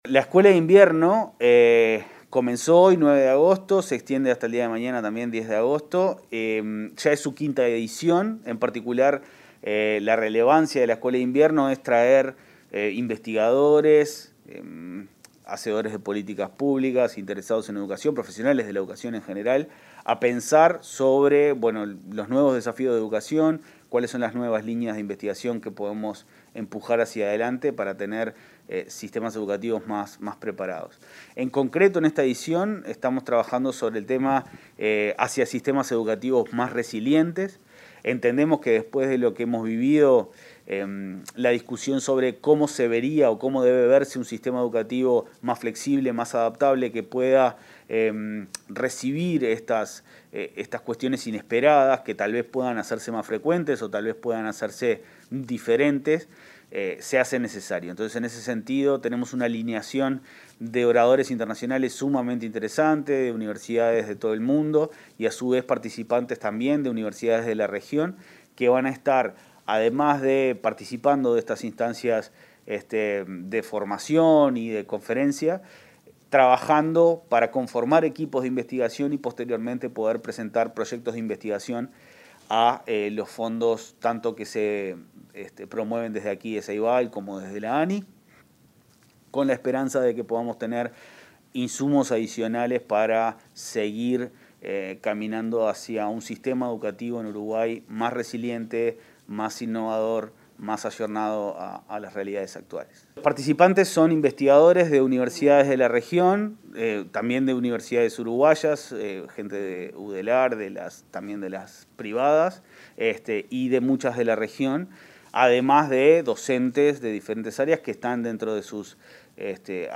Entrevista al presidente de Plan Ceibal, Leandro Folgar